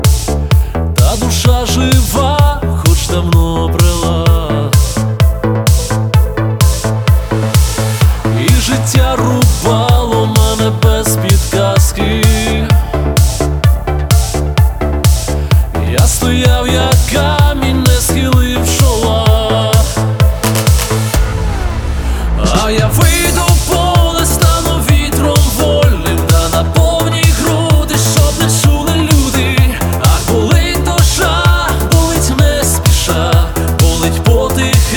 Pop
Жанр: Поп музыка / Украинские